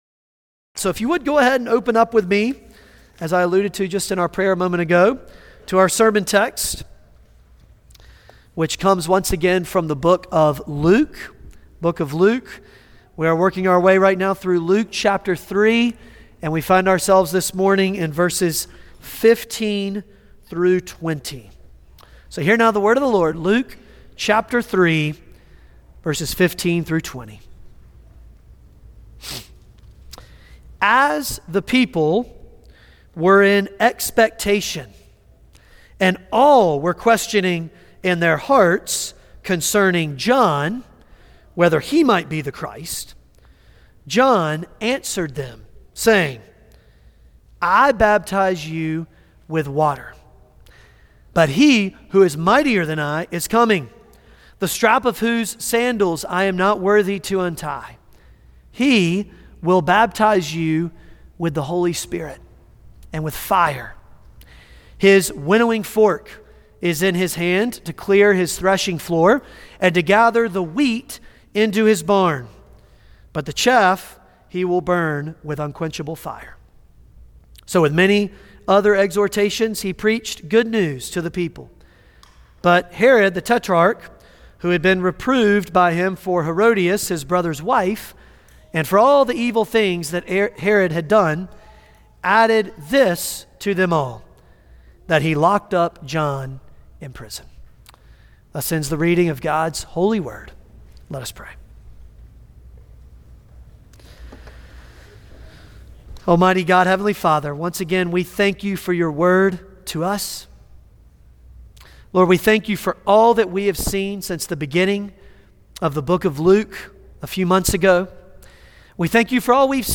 Luke Passage: Luke 3:15-20 Service Type: Sunday Morning Luke 3:15-20 « John The Baptist